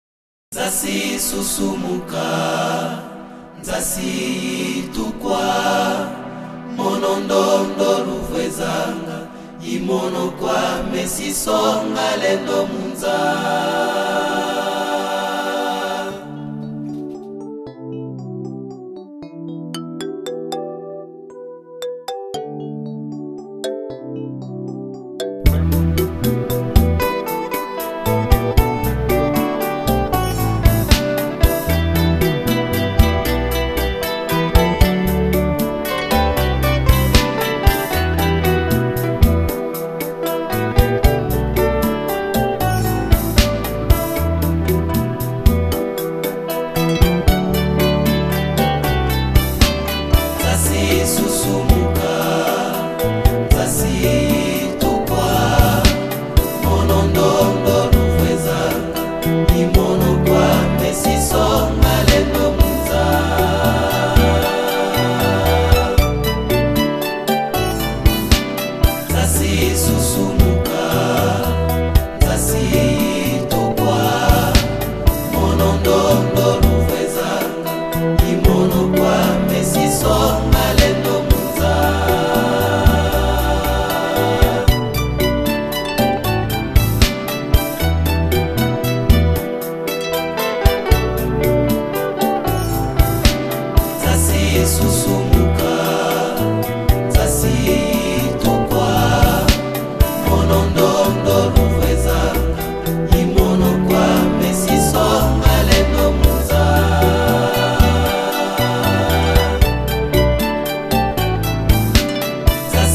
Liste des cantiques